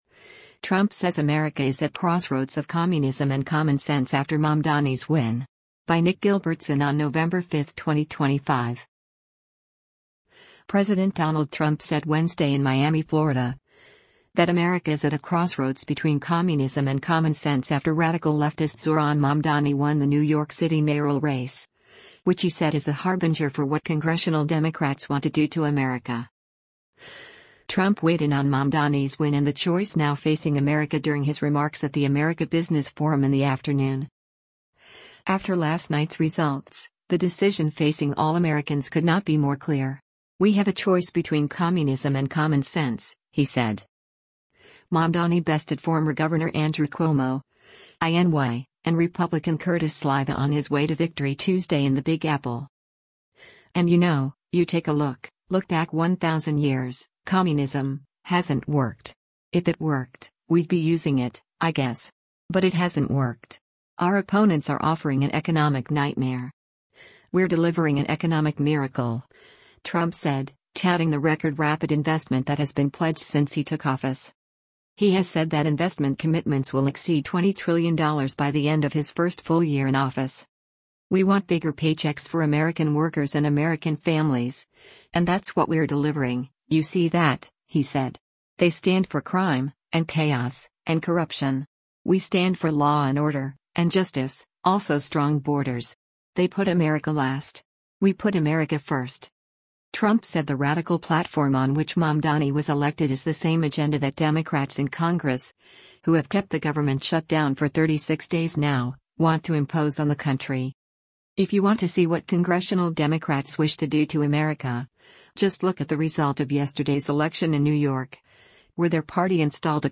Trump weighed in on Mamdani’s win and the choice now facing America during his remarks at the America Business Forum in the afternoon.